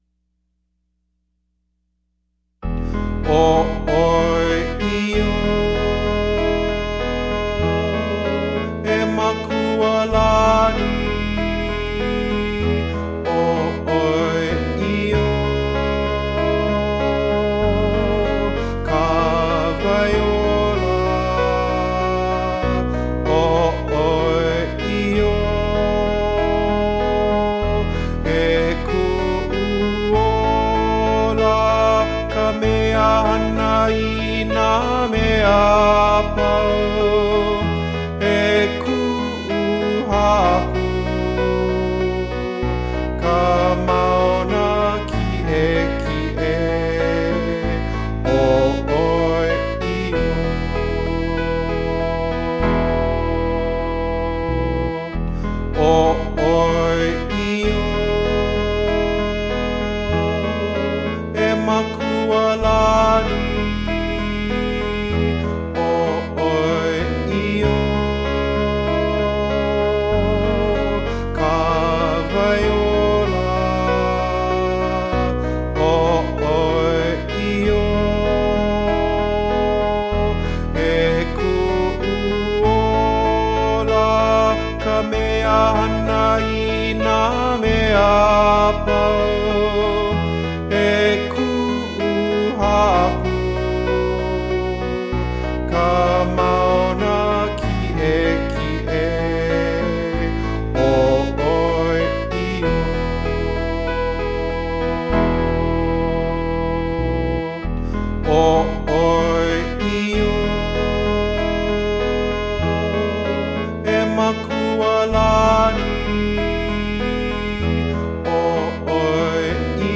Soprano   Instrumental | Downloadable